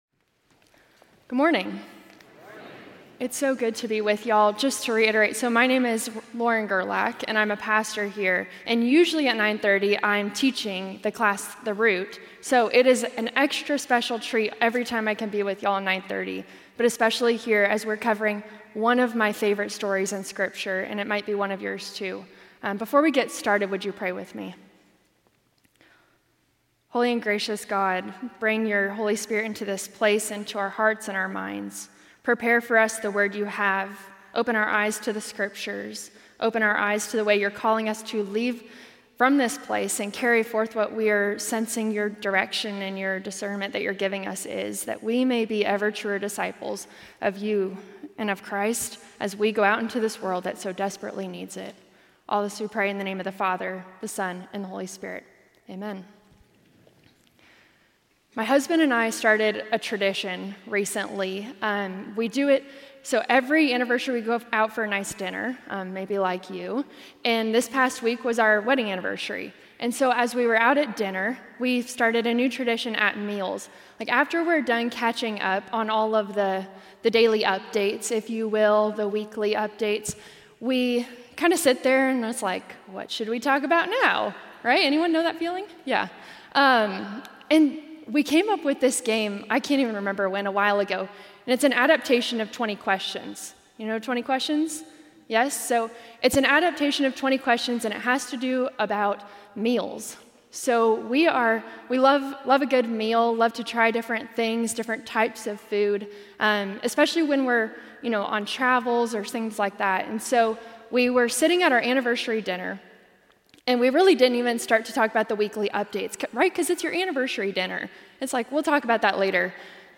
A message from the series "Jesus Is Good News."